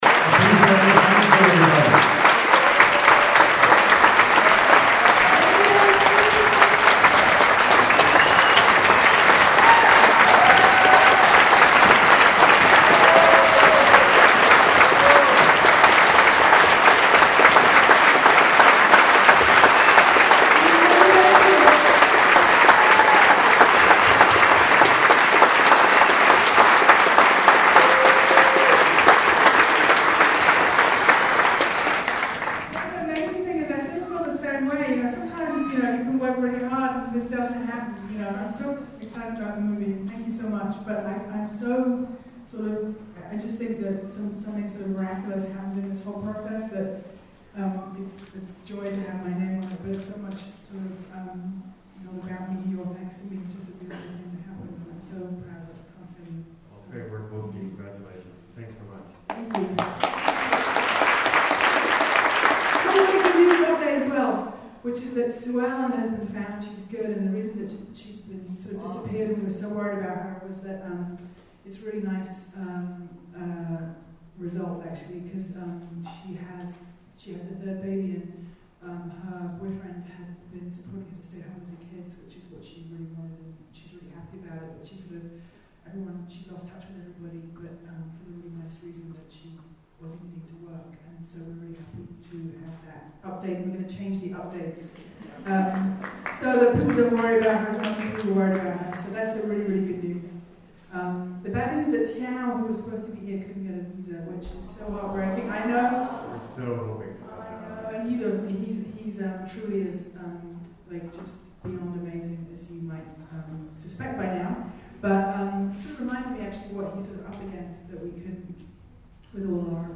wasteland_qa.mp3